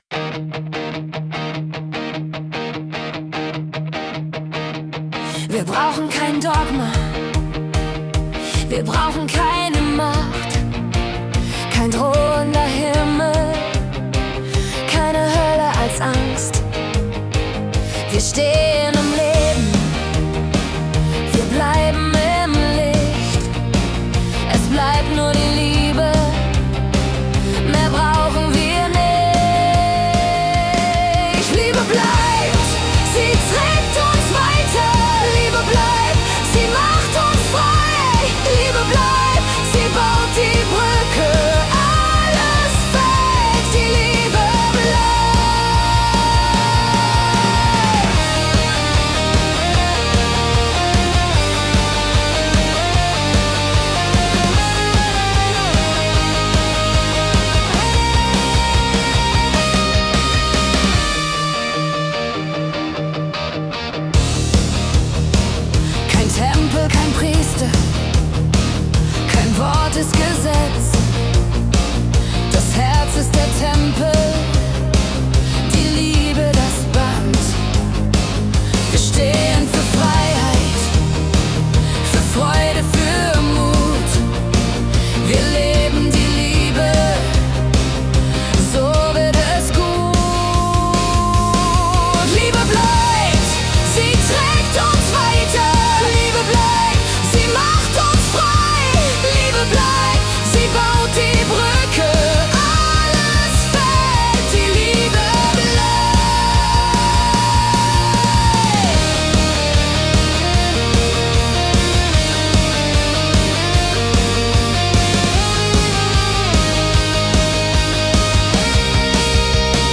Heavy MetalBPM ~150